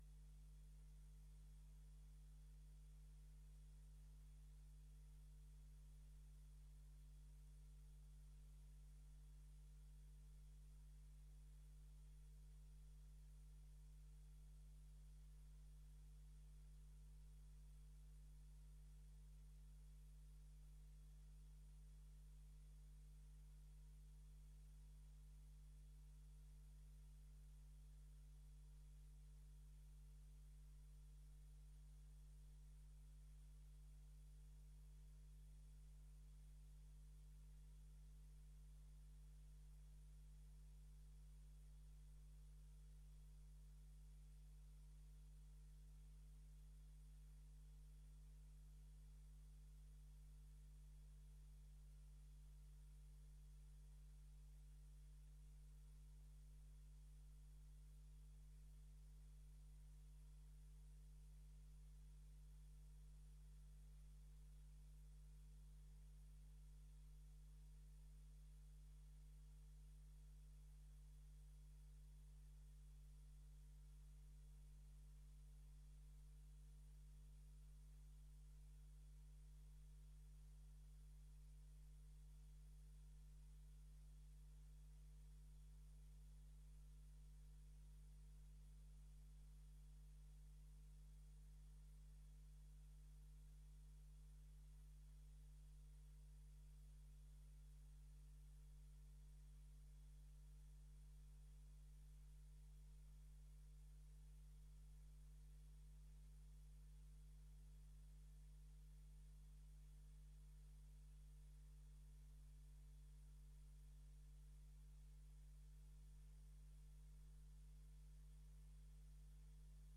Gemeenteraad 20 januari 2025 20:30:00, Gemeente Dalfsen